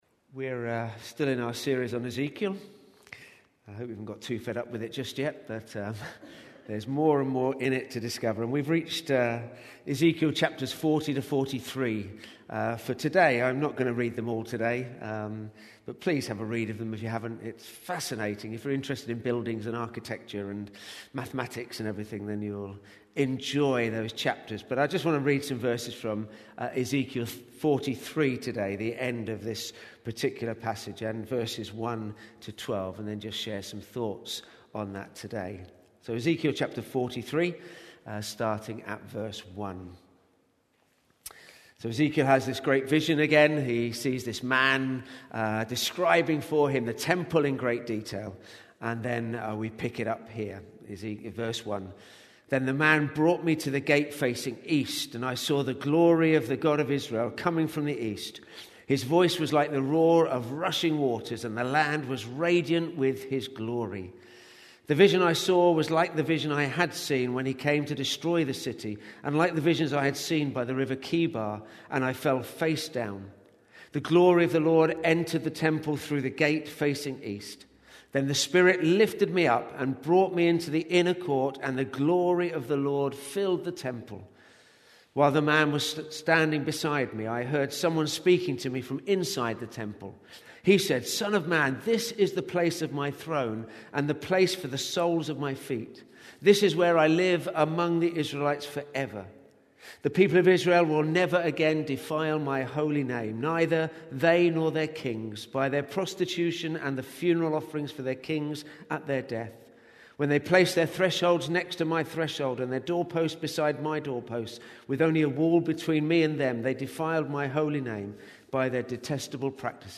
Today’s sermon is based on Ezekiel …